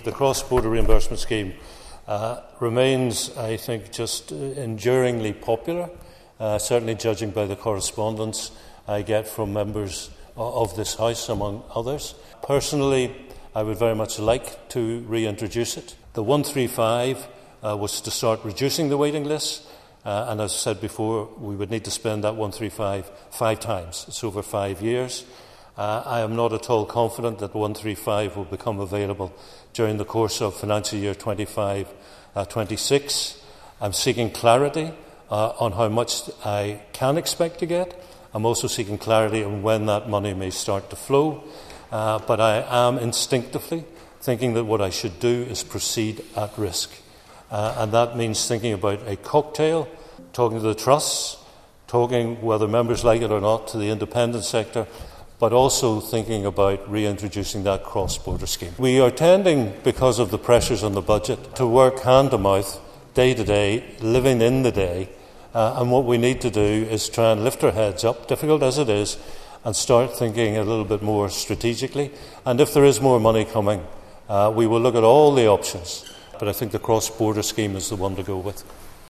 Mr Nesbitt told the Assembly that the recently agreed Programme for Government promises up to £135 million, but no figures have been agreed.